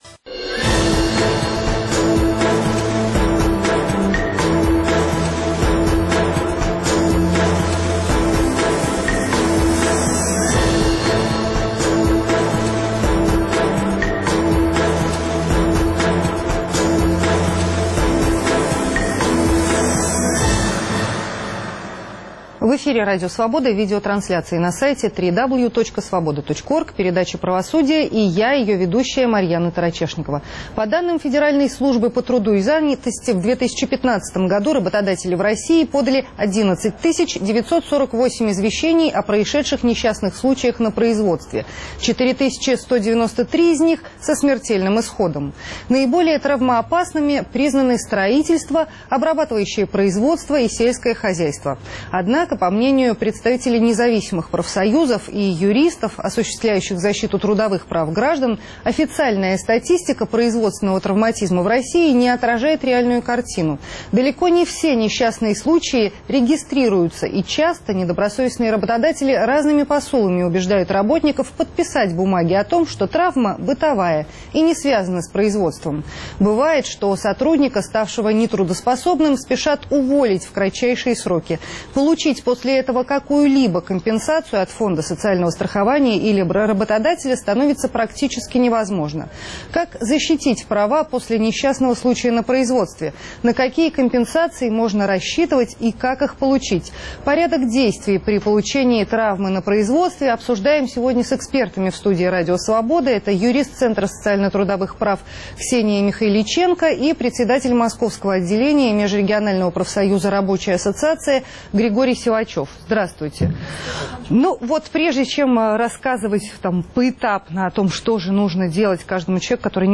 Что делать, если вы получили травму на работе? Порядок действий при получении травмы на производстве обсуждаем с экспертами в студии Радио Свобода